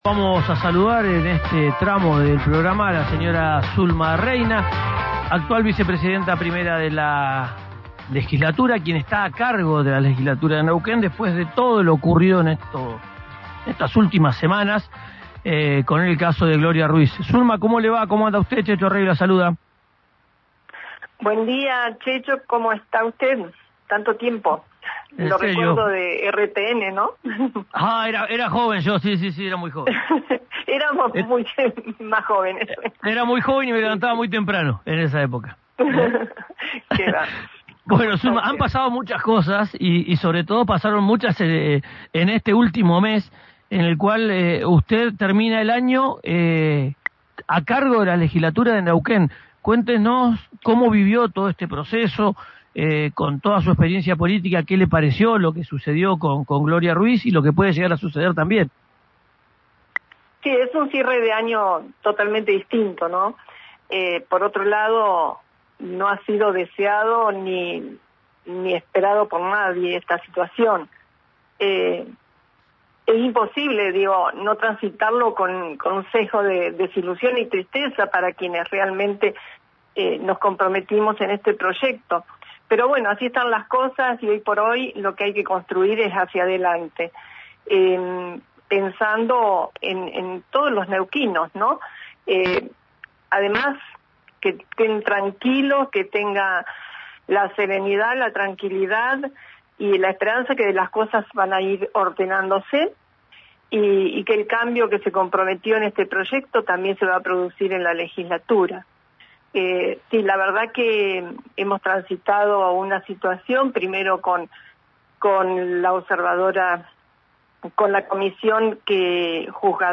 Escuchá a Zulma Reina en RÍO NEGRO RADIO